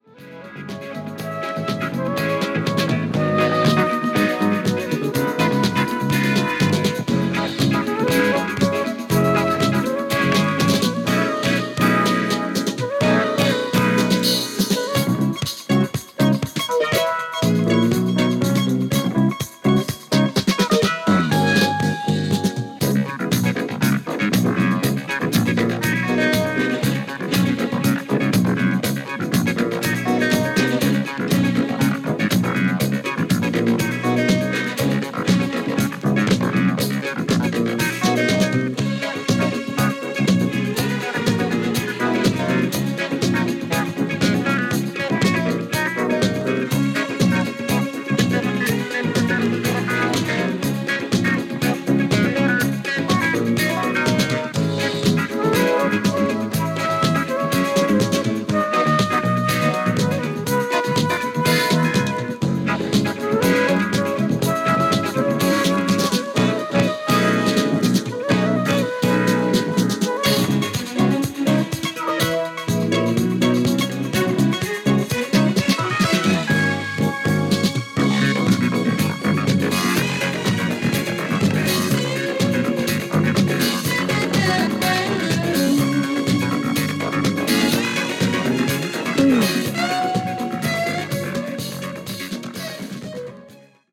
陽気な楽曲からダンスな楽曲をまとめた